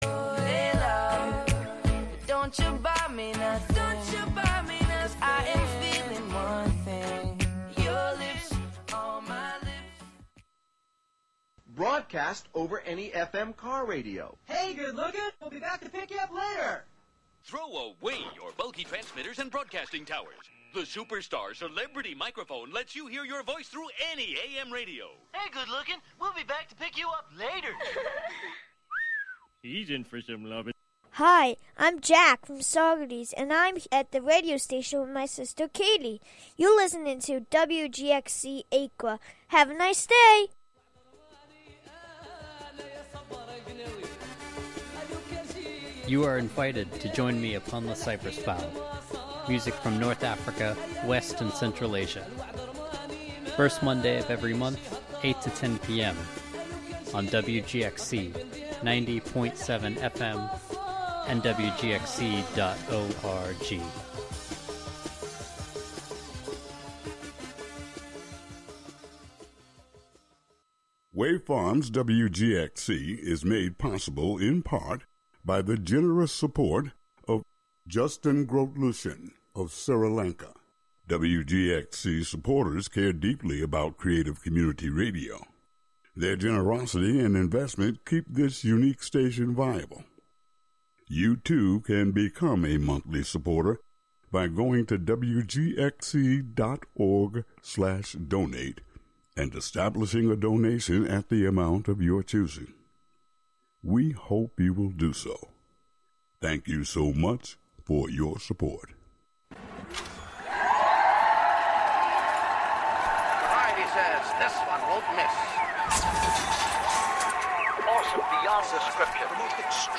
Music, talk, and schtick, just like any variety show.